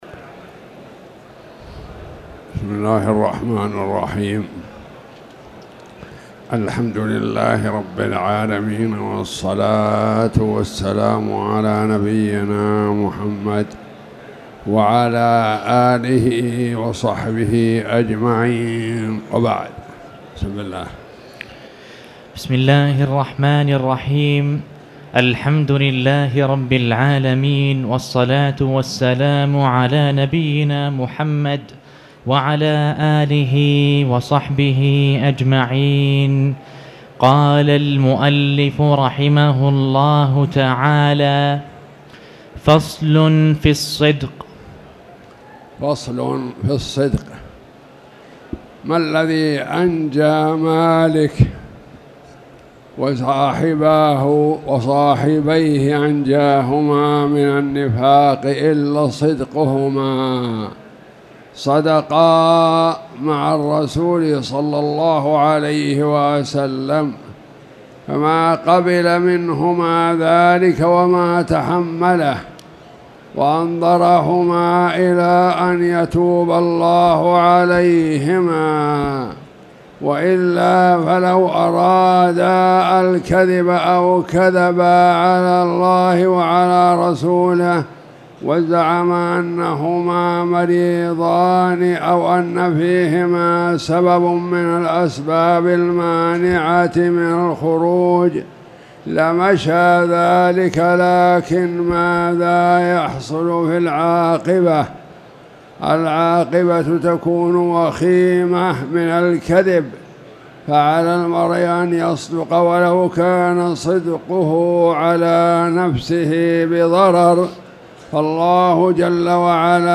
تاريخ النشر ٦ جمادى الأولى ١٤٣٨ هـ المكان: المسجد الحرام الشيخ